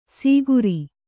♪ sīguri